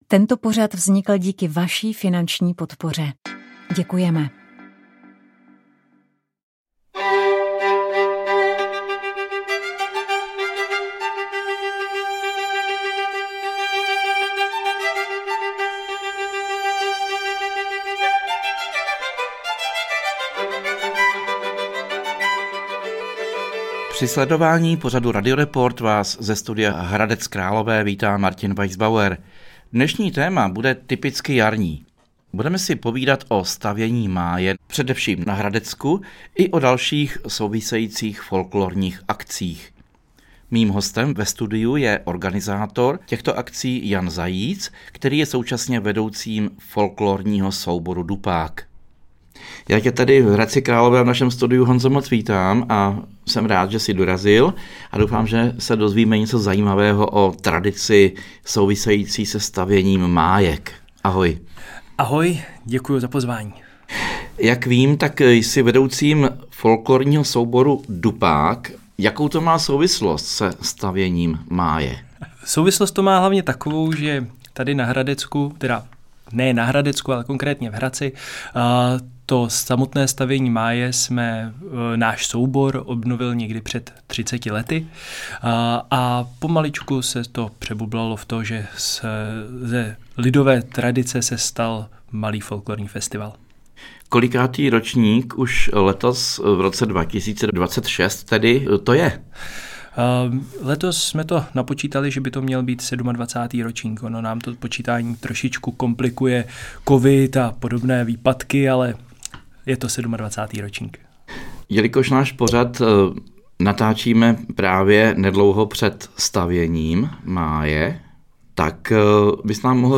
S Proglasem jsme byli u toho!
Konference se věnovala vztahu bezpečnosti státu a rodiny, proměnám autority i otázkám výchovy bez násilí. V úvodu vystoupil ministr práce a sociálních věcí Aleš Juchelka, který zdůraznil důležitost bezpečí pro děti i rodiny.